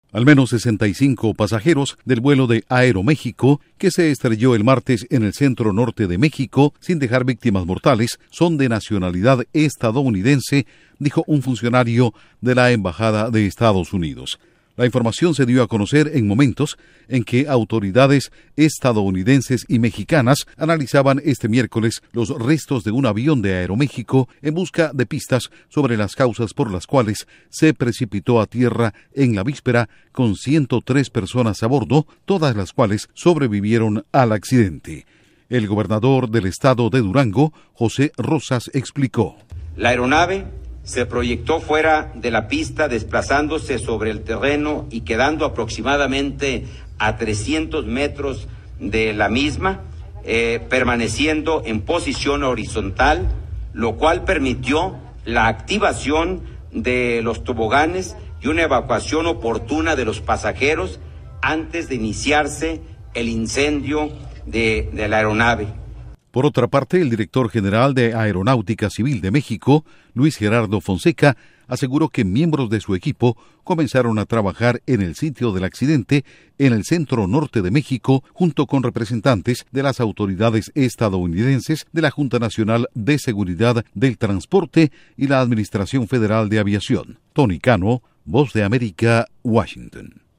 Al menos 65 pasajeros del vuelo accidentado en México son estadounidenses dice funcionario de embajada EE.UU. Informa desde la Voz de América en Washington
Duración: 1:30 1 audio de José Rosas/Gobernador del estado de Durango MX